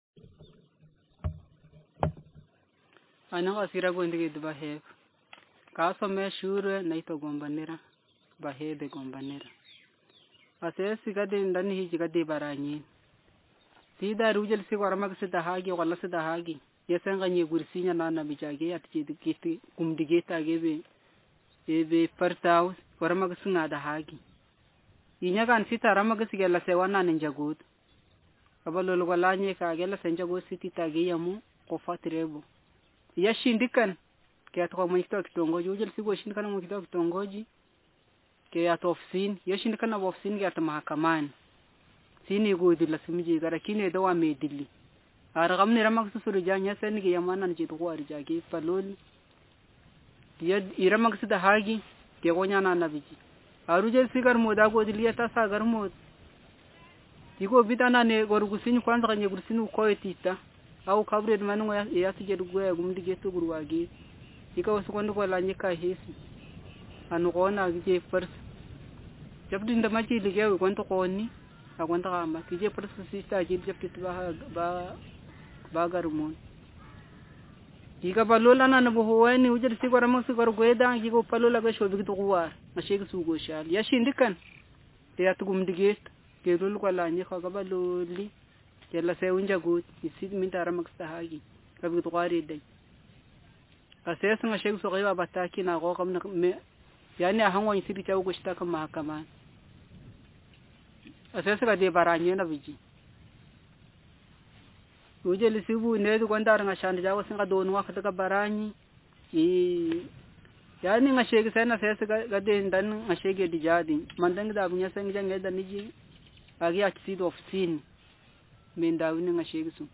Speaker sexm
Text genretraditional narrative